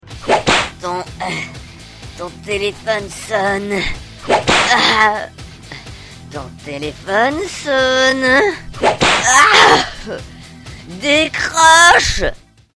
Sonneries de portable, format mp3